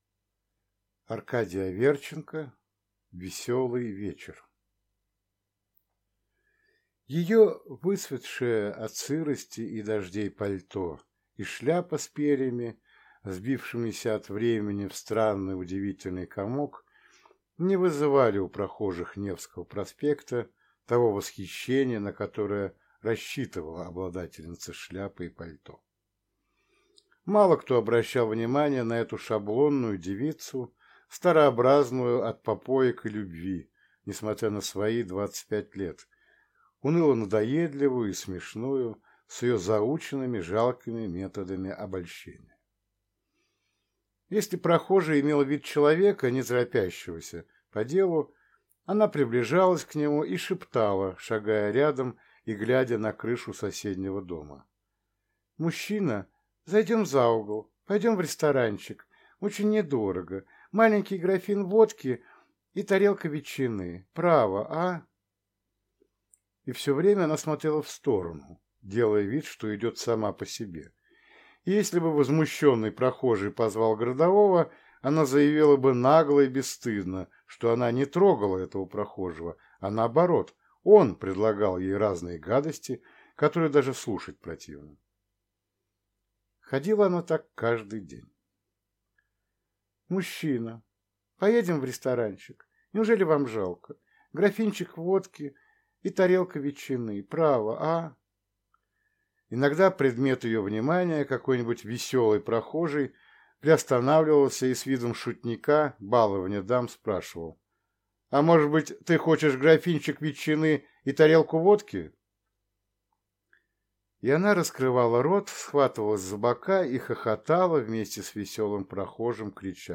Аудиокнига Веселый вечер | Библиотека аудиокниг